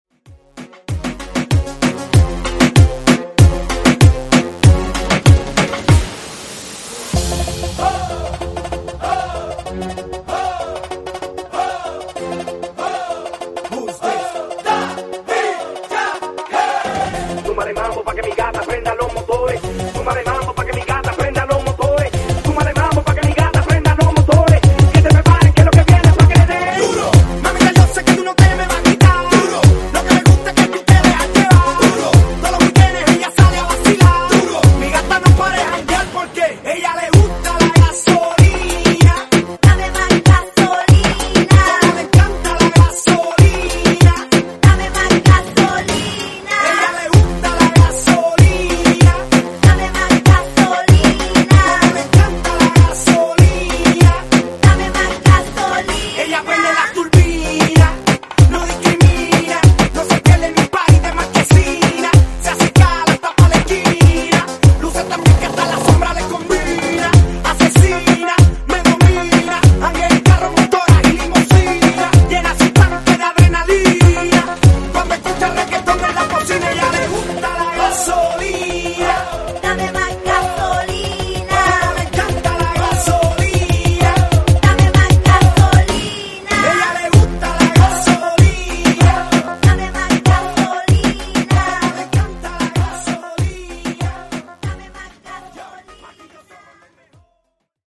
Genre: RE-DRUM Version: Clean BPM: 82 Time